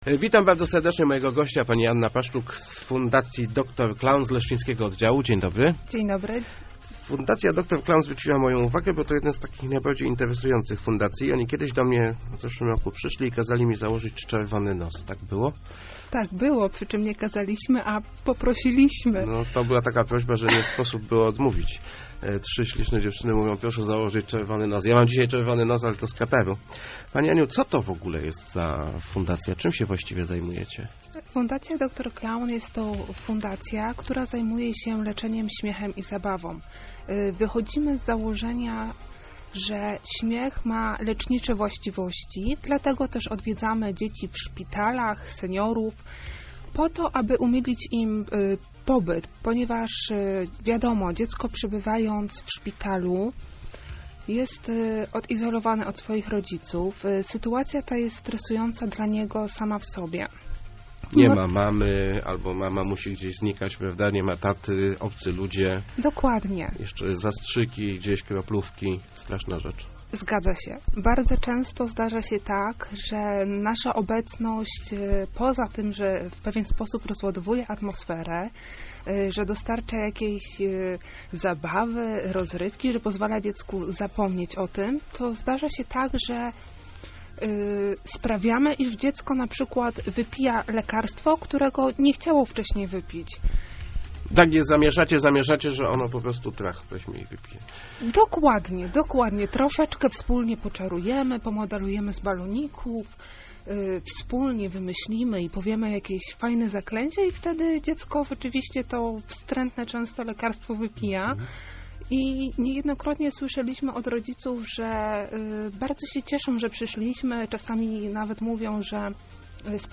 Śmiech rzeczywiście może leczyć - mówiła w Rozmowach